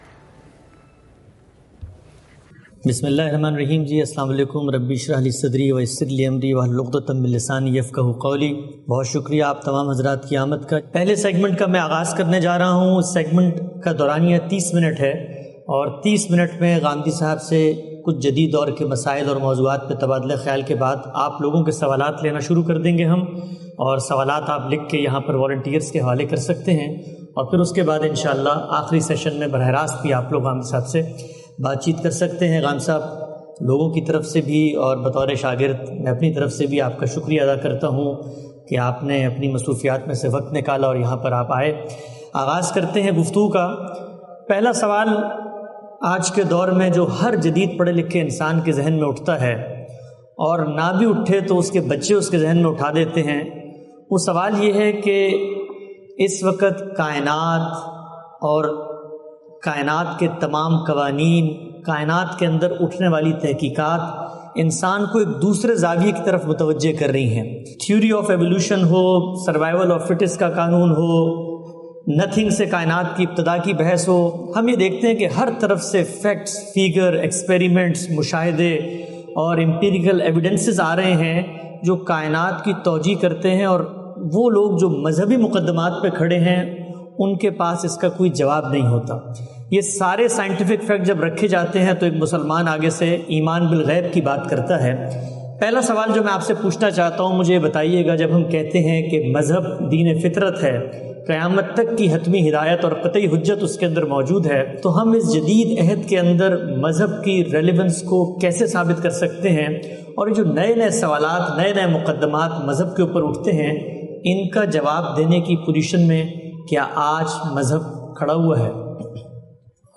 Principal Research Fellow of Ghamidi Center of Islamic Learning, Javed Ahmed Ghamidi, addressed a gathering of Pakistani American Physicians on July 2nd, 2021, at APPNA 2021 held in Orlando, Florida.